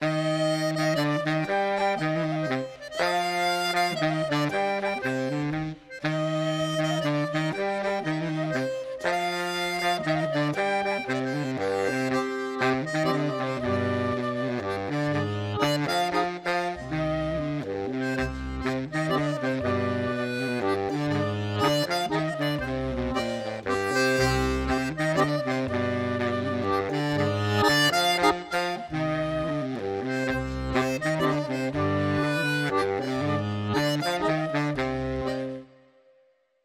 Mazurka